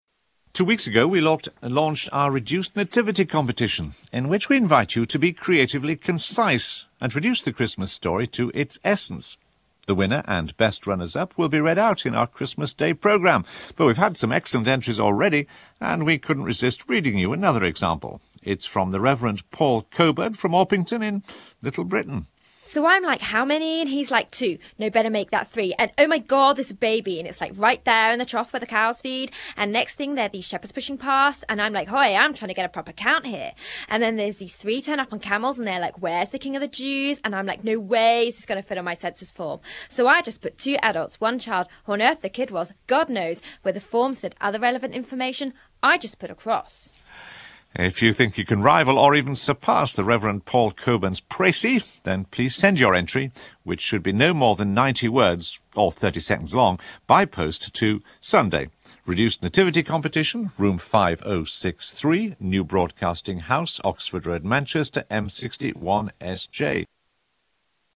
Format: Monologue
I sent in an entry and it was read out on the radio on Sunday 27th November 2005 as an example of the kind of thing they were looking for.
b) It was read out too fast, hence lasting 25 seconds when it should have taken a more leisurely 29.
this mp3 version which includes a brief introduction by the announcer, or this one which is just the monologue.